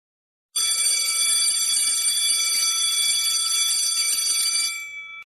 School Bell Téléchargement d'Effet Sonore
School Bell Bouton sonore